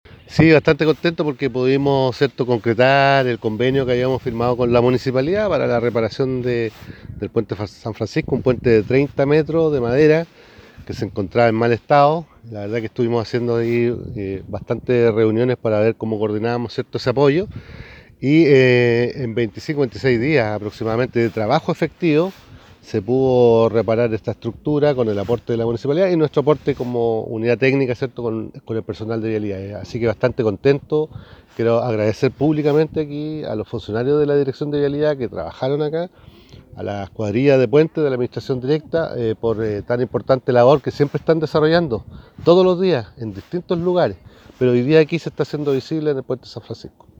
Durante el pasado fin de semana se realizó la ceremonia de inauguración de la reposición del Puente San Francisco, un anhelado proyecto por parte de los vecinos del sector distante a 8 kilómetros de Osorno por la Ruta 5 hacia el norte.
El Director Regional de Vialidad, Jorge Loncomilla señaló que en alrededor de 26 días se pudo realizar el trabajo de reposición, lo que se logró gracias al apoyo de la casa edilicia y los vecinos del sector.